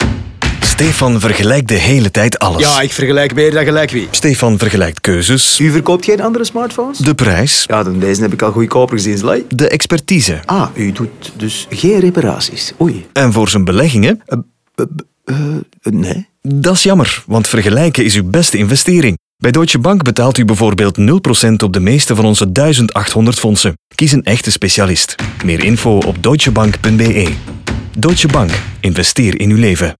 En laten we de radiospot niet vergeten, Hierin komt Stéphane aan het woord, een man die de hele tijd alles vergelijkt.
DeutscheBank-DeVergelijker-Radio-NL-30s-Fondsen-051217.mp3